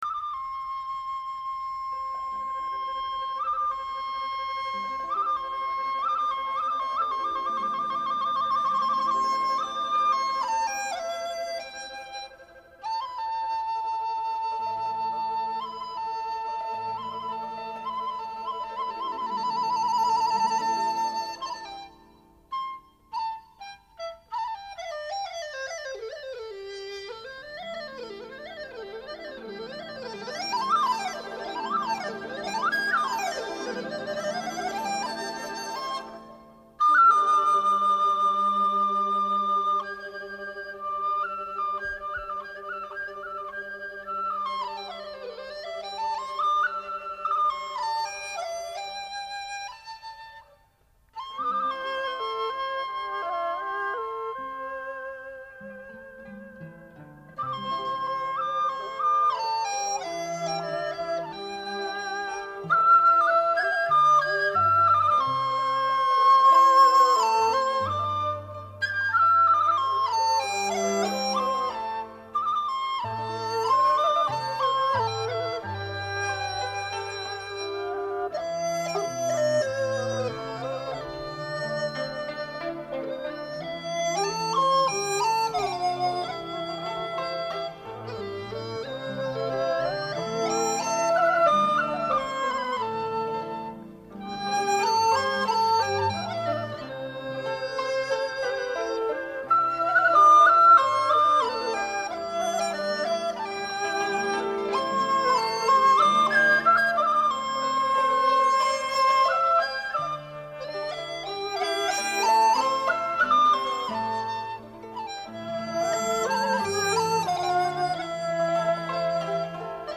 这些笛曲从大量的录音版中精选出来的，它们囊括了当代中国最优秀的笛子演奏家演奏的最有代表性的笛子独奏曲。
这些乐曲中，有相当一部分是50年代或60录制的，由于年代较远，音响效果不甚理想，然而，历史性录音的珍贵价值弥足珍贵。